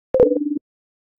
41_Hight_Temperature_Alarm.ogg